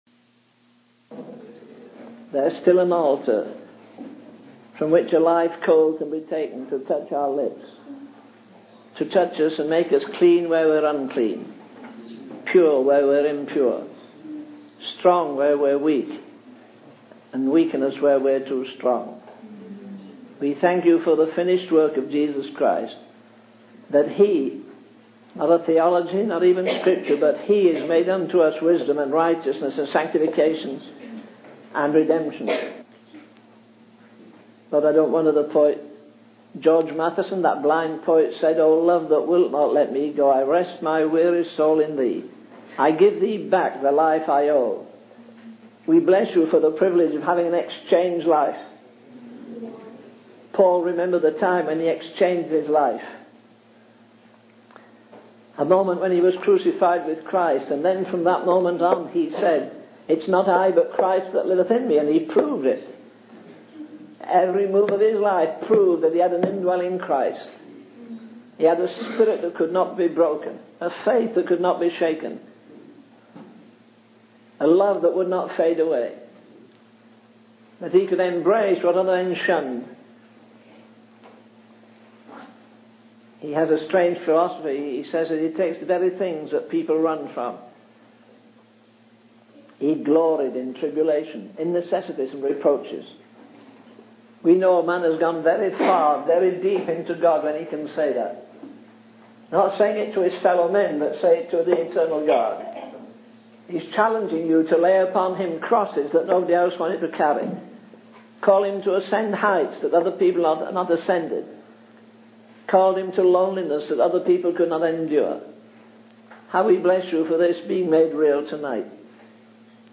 In this sermon, the preacher emphasizes the importance of contending for the faith once delivered to the saints, as mentioned in the book of Matthew, chapter six. He addresses the misconception that the Old Testament is not relevant today, which has caused upset among some people. The preacher highlights the significance of recognizing our spiritual poverty and need for God, even if we may have material wealth or social status.